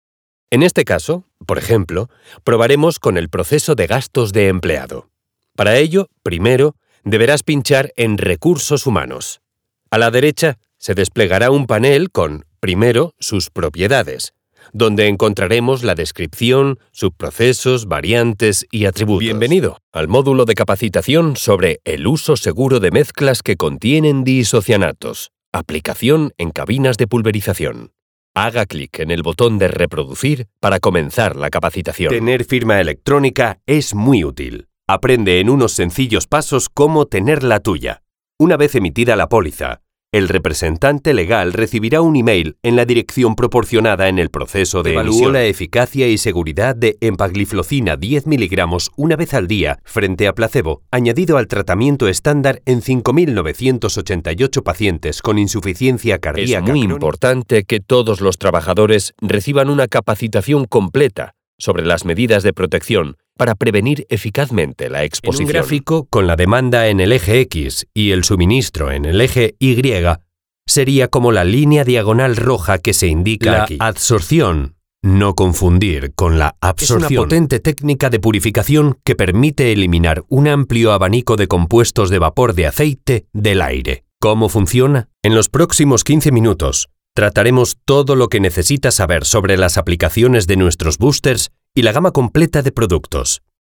E-learning
Passionate and dynamic voice with a mid-range tone and a “guy next door” spark.
Offering vocal techniques that are flexible and colorful.
STUDIOBRICKS ONE PLUS SOUND BOOTH
NEUMANN TLM 103